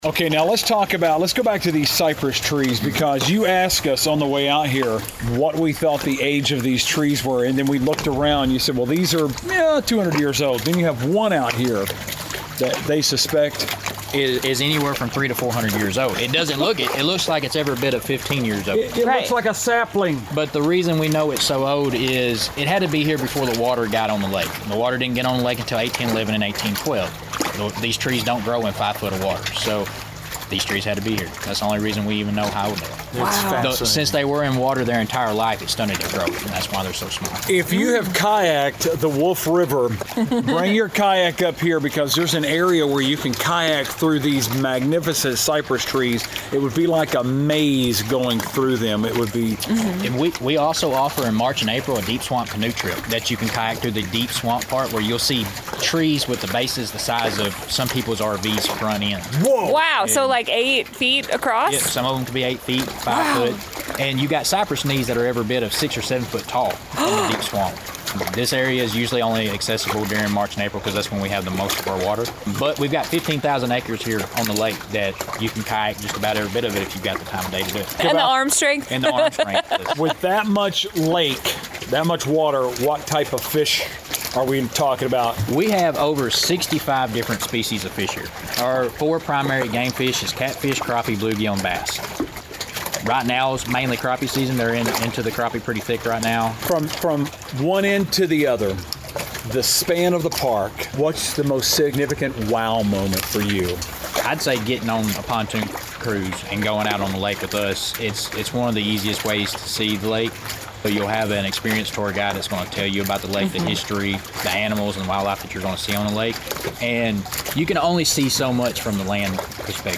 The sound of the waves lapping against the Cypress Trees made for a lovely chat about the most surprising park we’ve visited so far, Reelfoot Lake State Park!